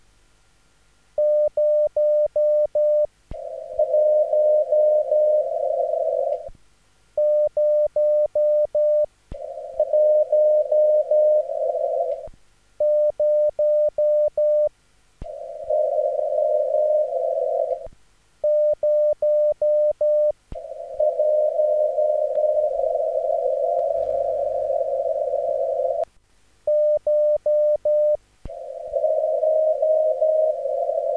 Tak silné vlastní odrazy jsem snad
Brilliant EME condx on Sunday. Such strong my own reflections probably I didn't heard yet.